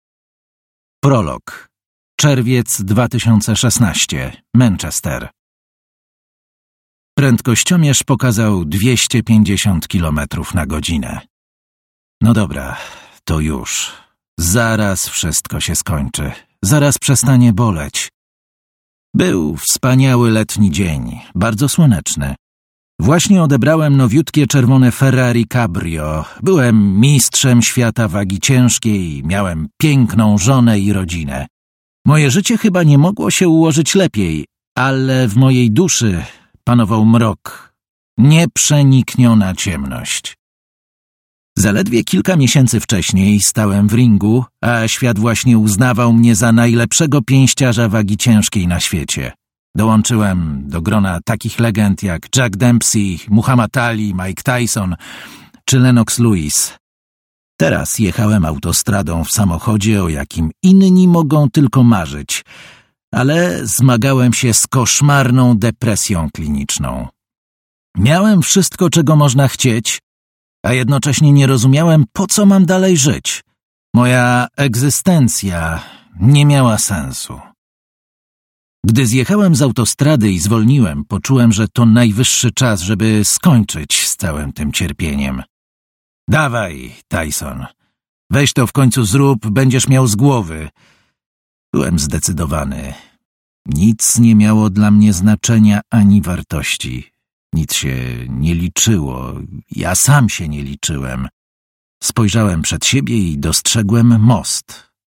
Kup audiobook: Tyson Fury. Bez maski Autor: Tyson Fury Kategoria: SQN Sport, biografie, literatura faktu.